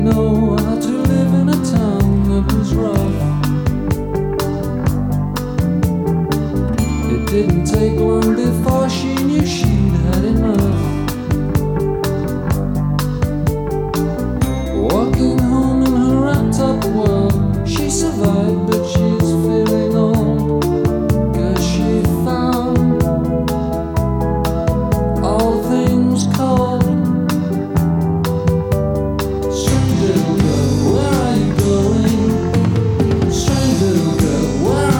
Жанр: Рок / Альтернатива / Панк